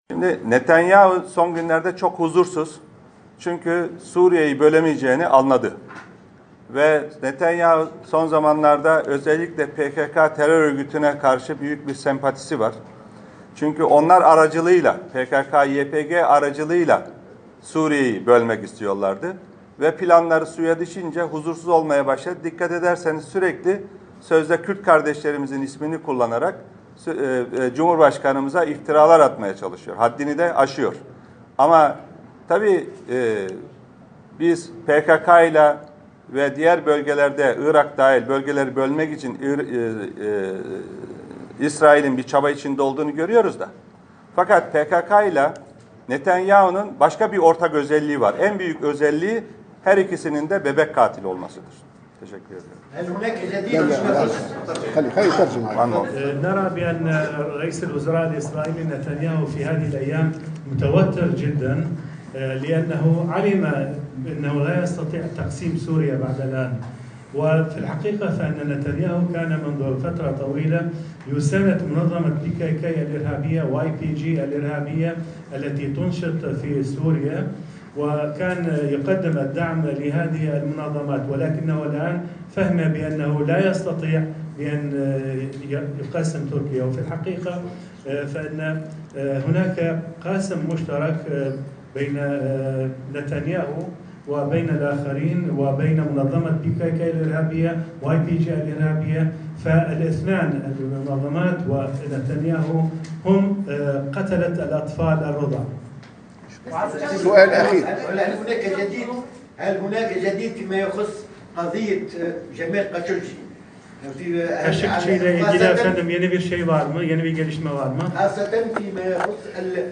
وأدلى جاويش أوغلو بالتصريحات في مؤتمر صحفي في تونس مع نظيره التونسي داعيا السعودية أيضا لعرض ما توصلت إليه في القضية على المجتمع الدولي.